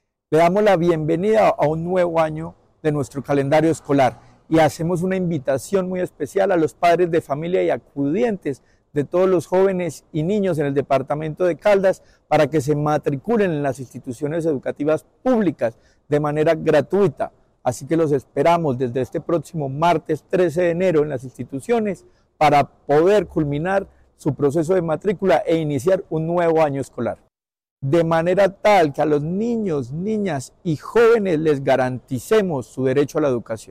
Secretario de Educación, Luis Herney Vargas Barrera.